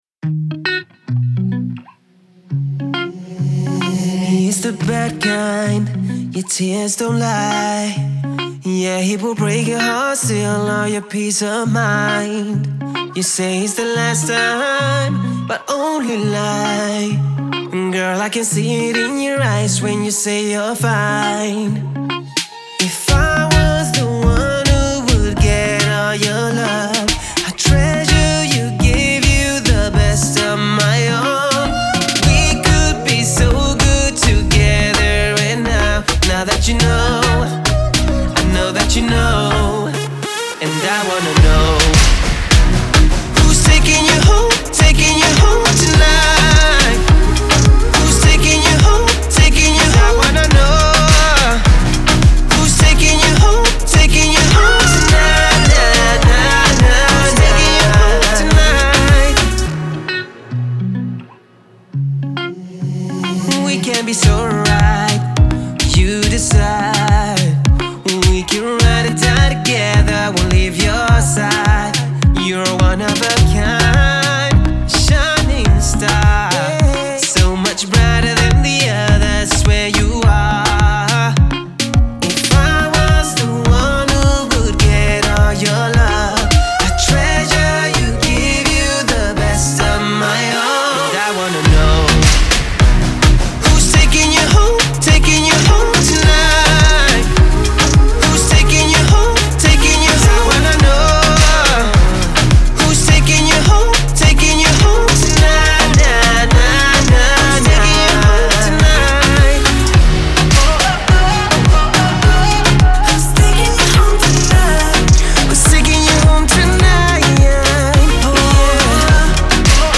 зажигательная поп-песня шведского певца